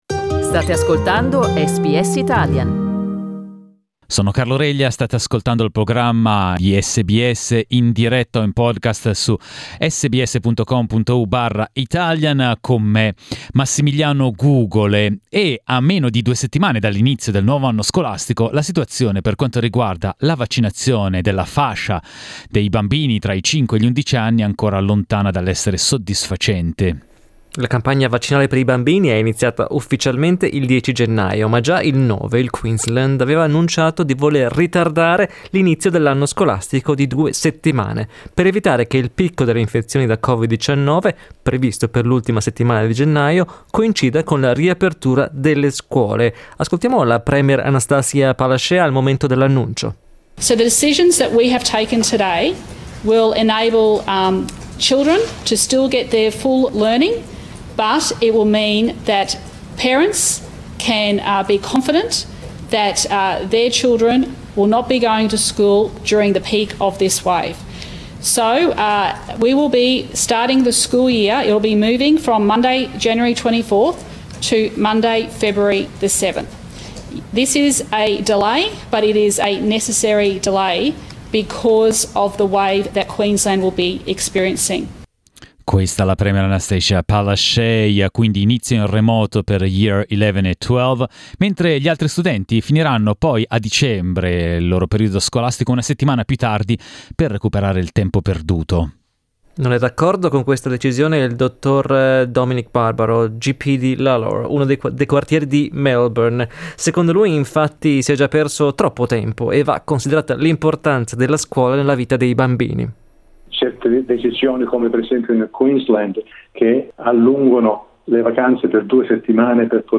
Ad una settimana dall'inizio della campagna vaccinale per i bambini, la percentuale di prime dosi è ancora bassa e l'imminente riapertura delle scuole suscita molti punti interrogativi. Le testimonianze di un medico e di una mamma.